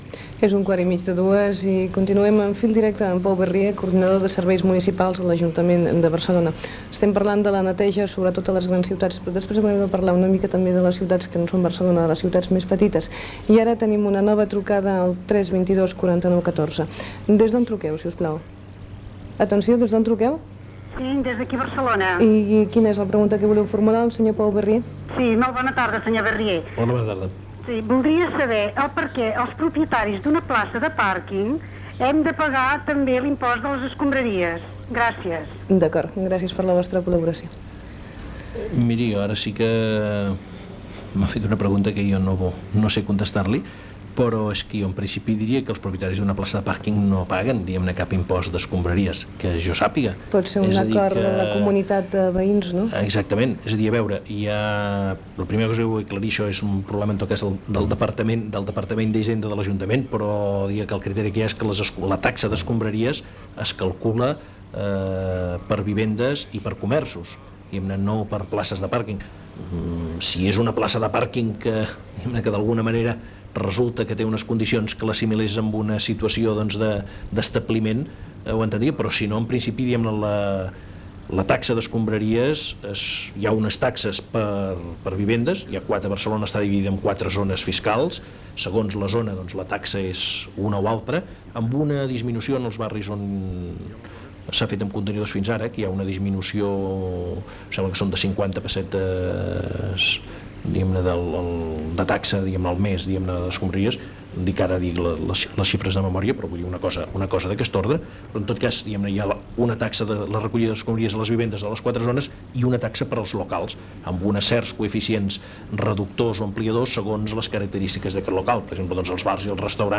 Gènere radiofònic Participació